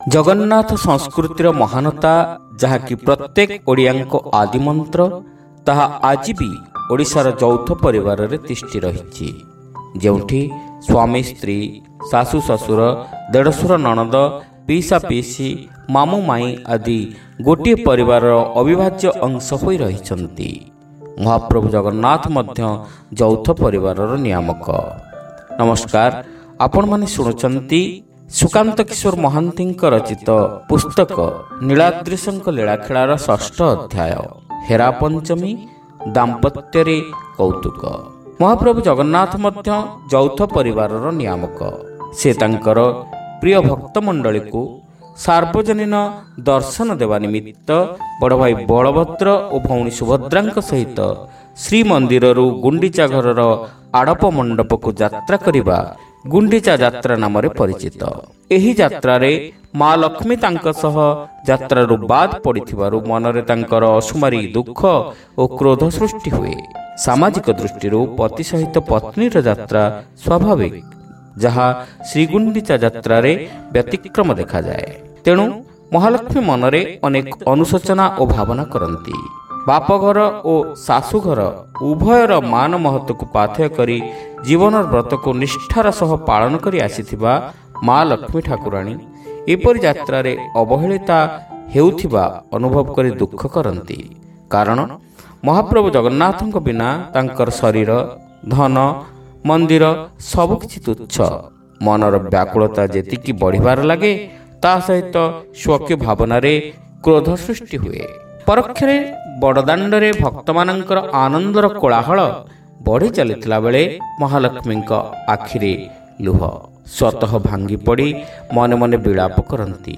ଶ୍ରାବ୍ୟ ଗଳ୍ପ : ହେରାପଞ୍ଚମି ଦାମ୍ପତ୍ୟରେ କୌତୁକ